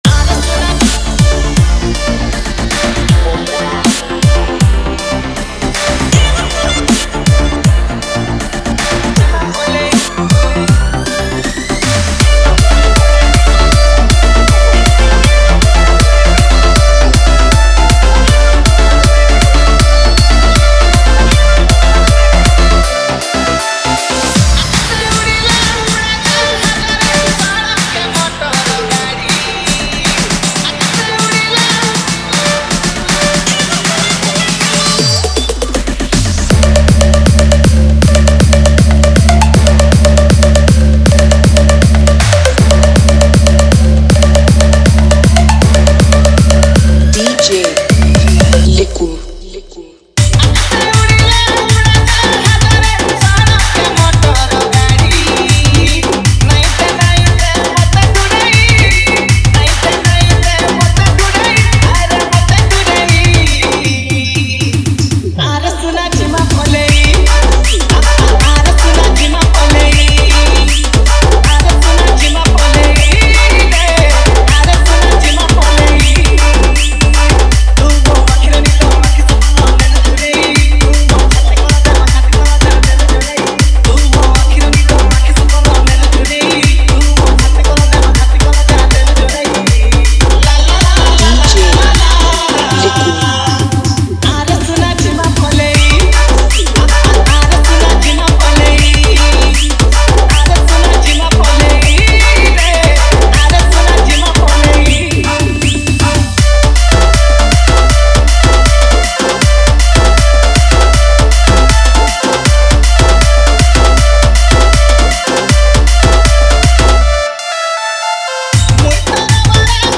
Sambalpuri Edm Tapori Mix